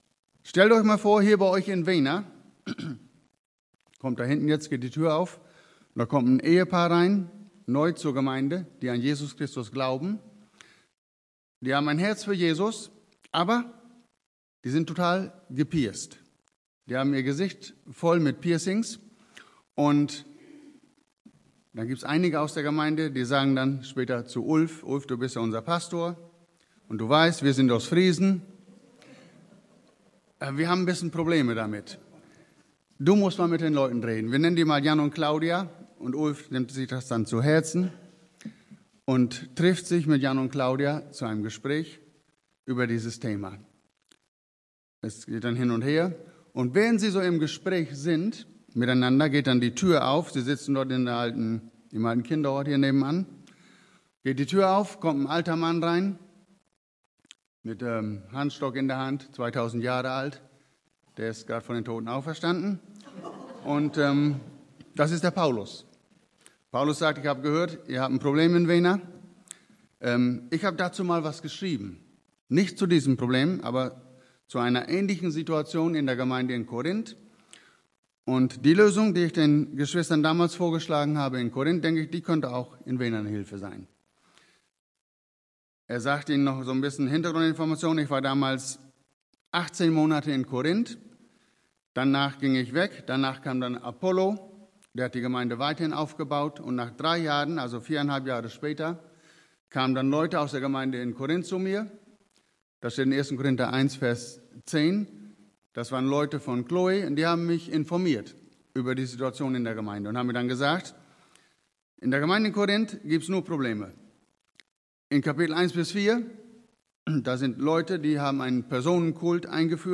Was/wer bestimmt meine Freiheit als Christ Prediger: Gastprediger Predigten Serien Gastprediger Details Datum: 07.09.2025 Bibelstelle: 1. Korinther 8 v1-13